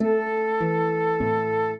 flute-harp
minuet7-10.wav